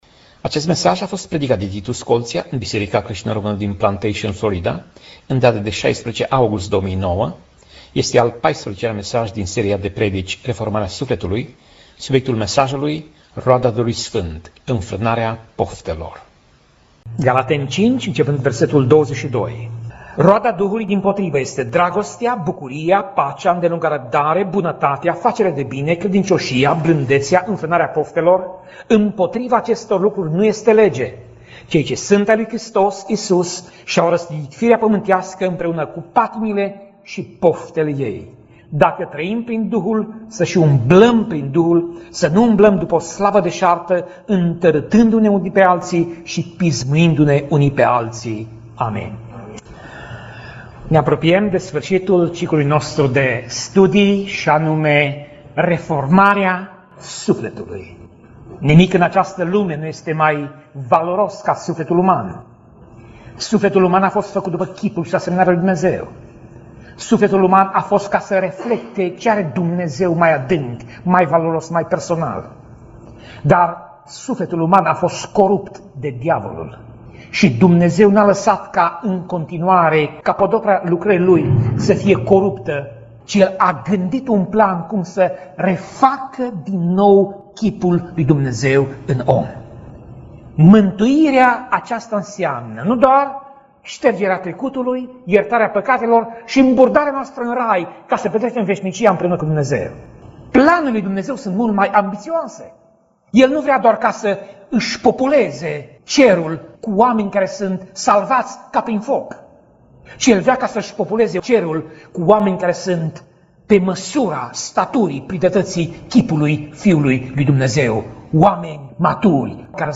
Pasaj Biblie: Galateni 5:22 - Galateni 5:26 Tip Mesaj: Predica